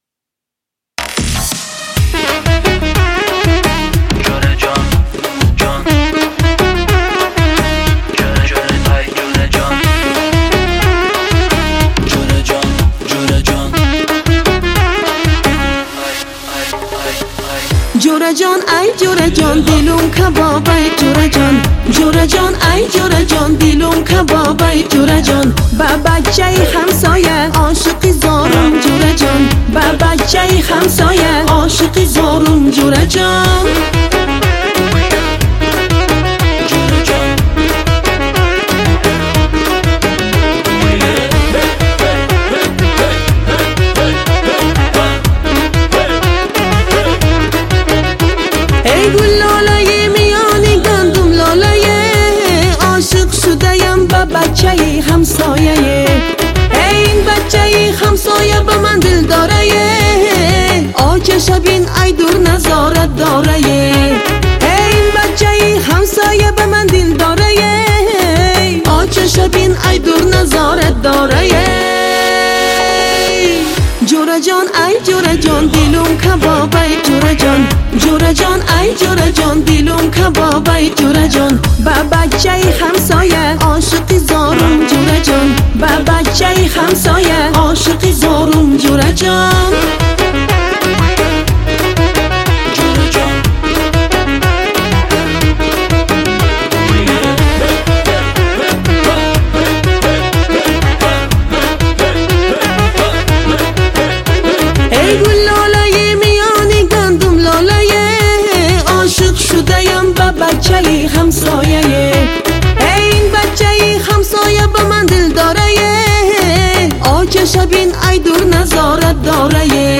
Трек размещён в разделе Узбекская музыка.